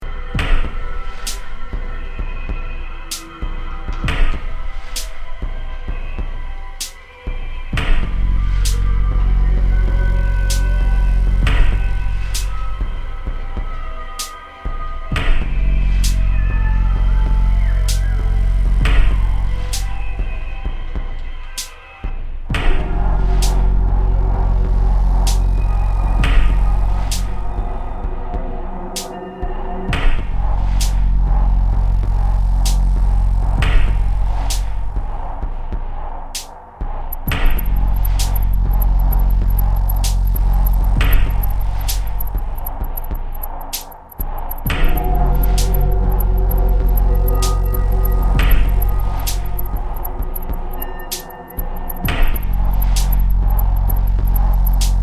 Sublime electronic scapes with a poetic approach